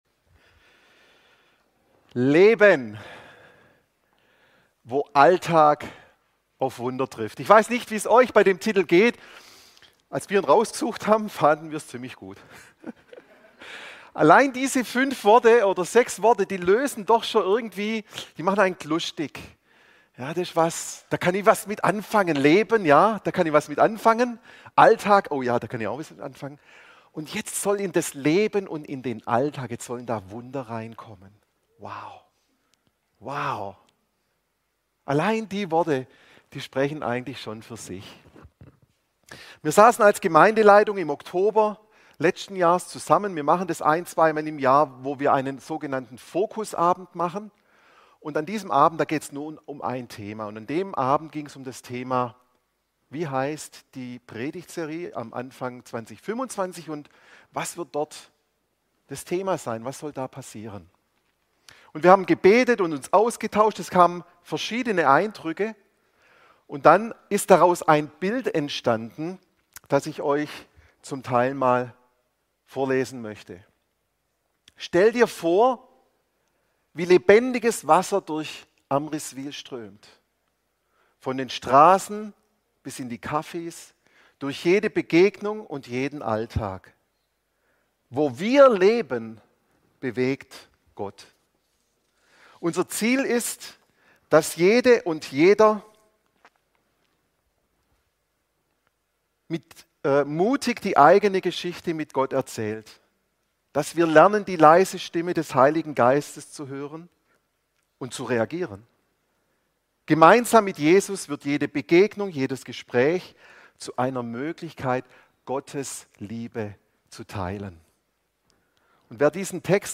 Die neueste Predigt